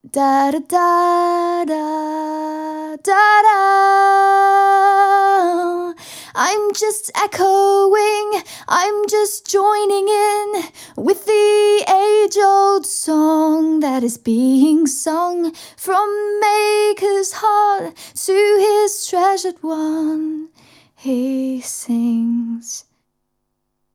Échantillons sonores Audio Technica AT-4050
Audio Technica AT-4050 - damski wokal